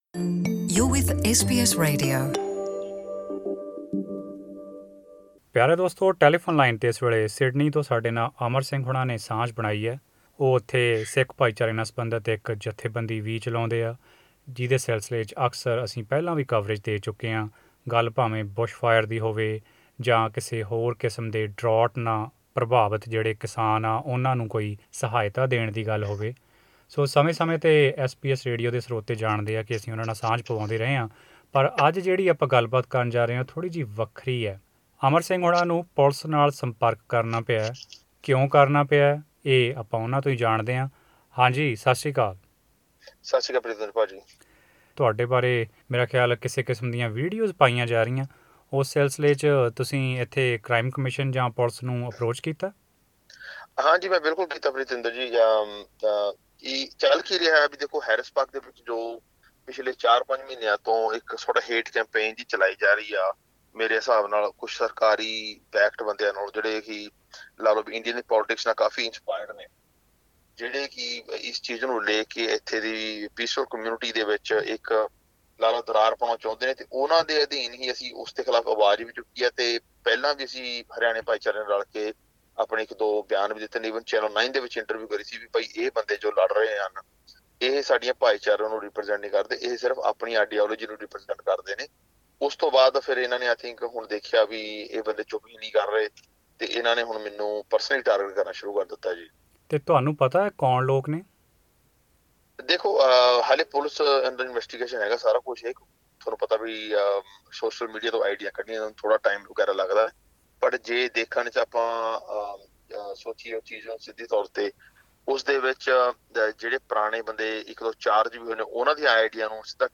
In an interview with SBS Punjabi